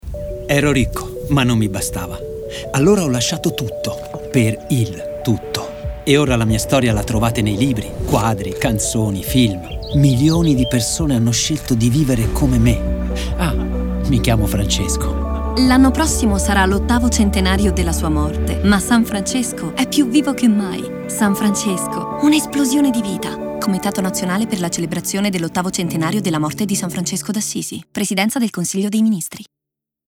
Lo spot radio
san-francesco_radio_30.mp3